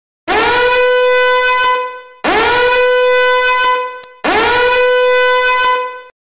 DANGER ALARM MEME Sound Button
Danger alarm meme sound button is a short, punchy audio clip that people love using in memes, gaming streams, and reaction edits.